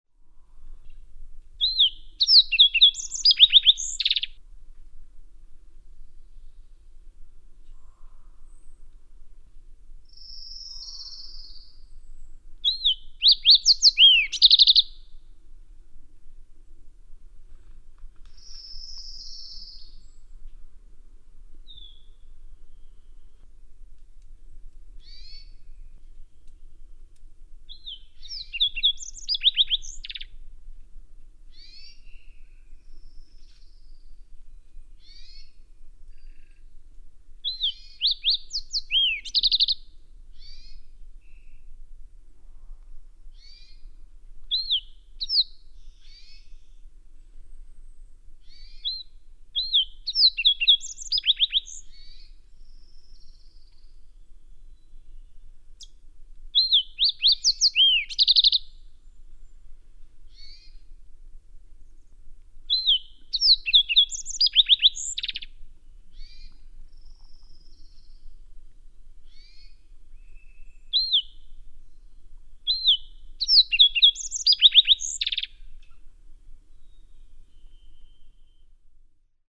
The "sooty" fox sparrow:
591_Fox_Sparrow.mp3